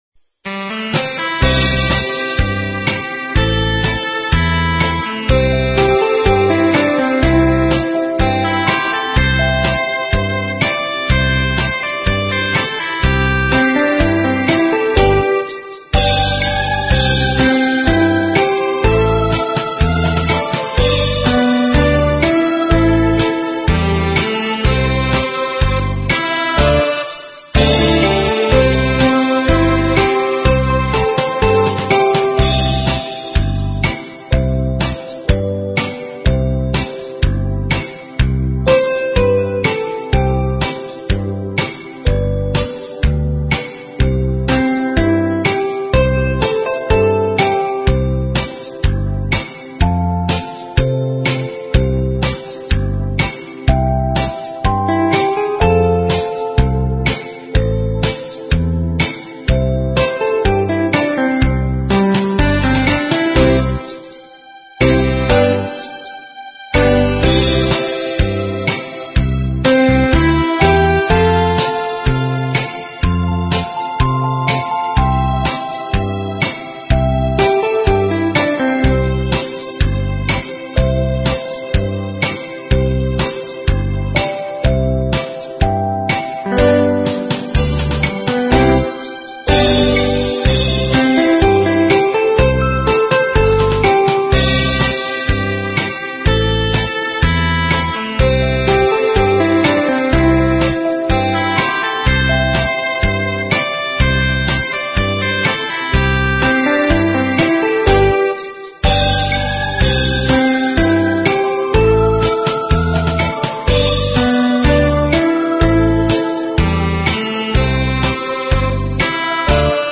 【★반주.명상.가사★】/♬~반주.연주.경음악